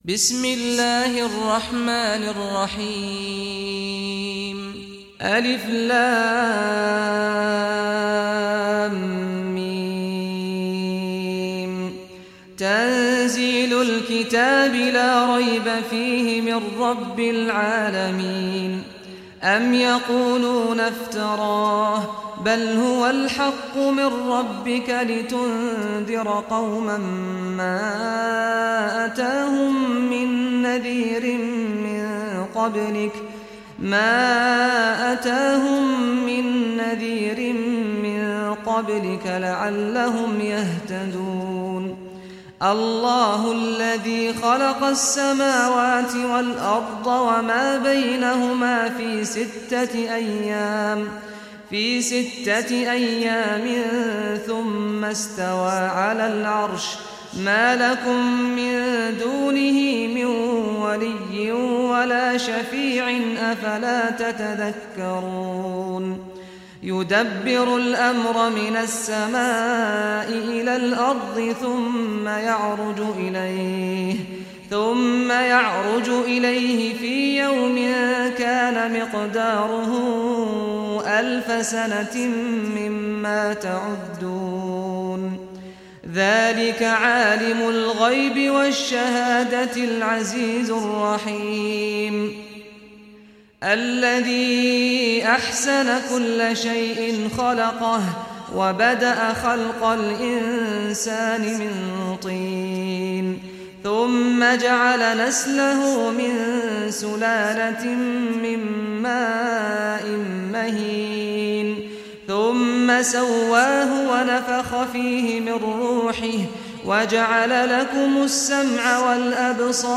Surah As-Sajda, listen or play online mp3 tilawat / recitation in Arabic in the beautiful voice of Sheikh Saad al Ghamdi.
32-surah-sajdah.mp3